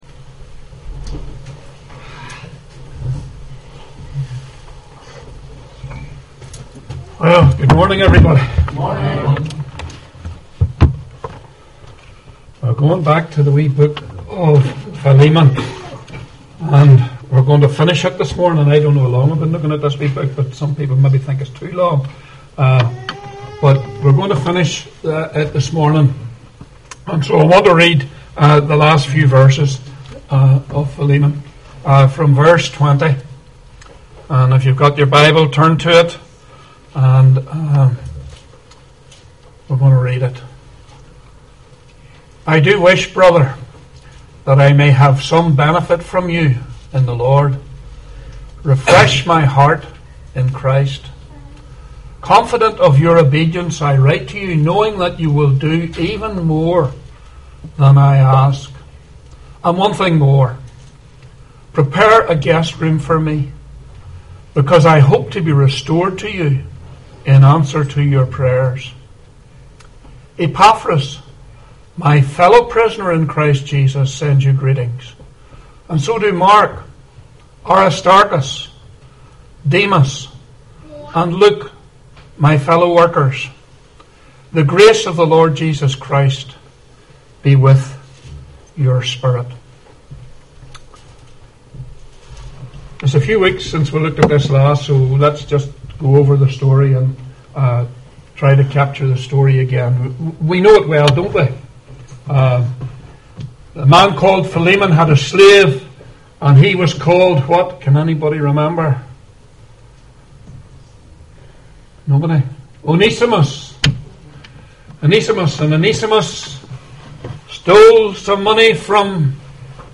Philemon Passage: Philemon 1:20-25 Service Type: 11am Philemon 20-25 New International Version